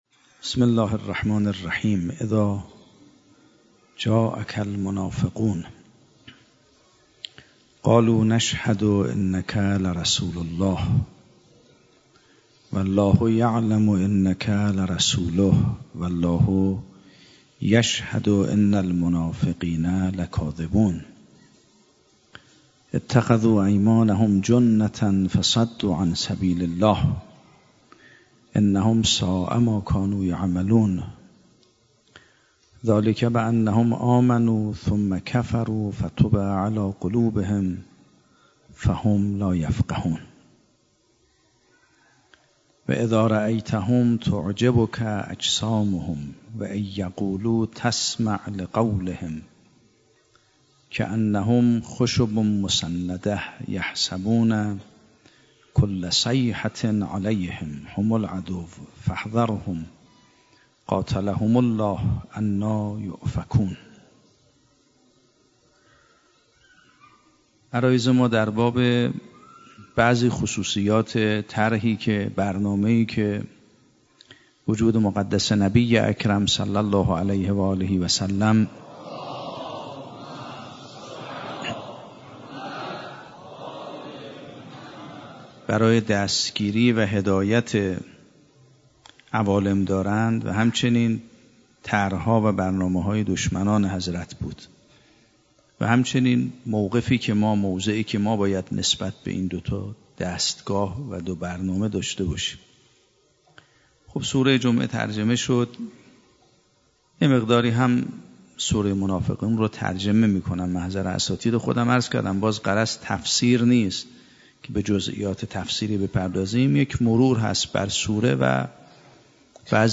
شب پنجم محرم 97 - هیئت ثارالله - سخنرانی